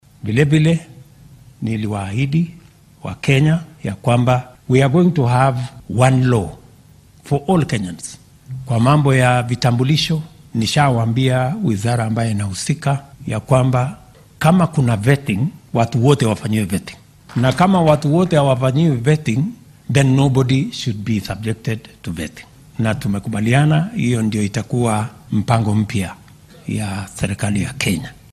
Arrintan ayuu ka hadlay xilli qaar ka mid ah muslimiinta wadanka ku nool uu ku casuumay munaasabad afur ah oo shalay maqribkii lagu qabtay xarunta madaxtooyada State House ee magaalada Nairobi.